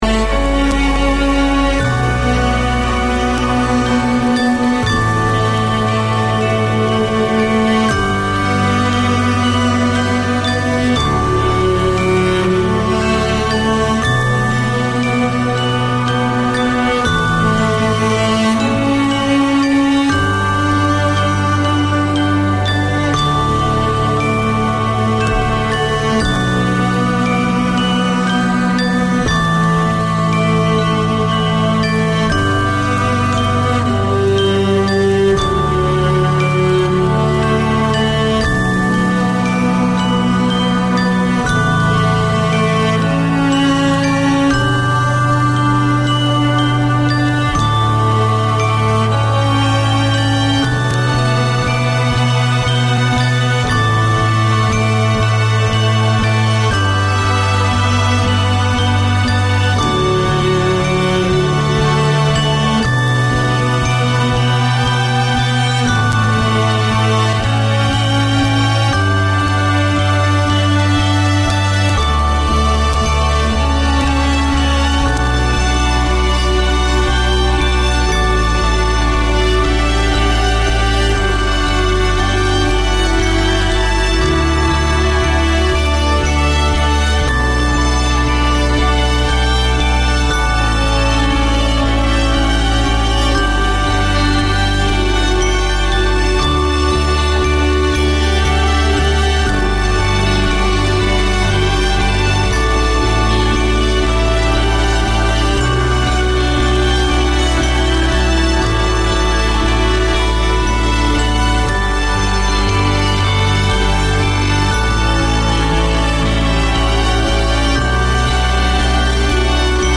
This Philippine cultural radio show is a lively hour with folk, ethnic and original Filipino music, Philippines news, community bulletins, sports updates, interviews, opinions and discussions seasoned with Filipino showbiz news and cultural highlights.